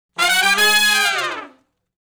012 Fast Climb Up (Ab) unison.wav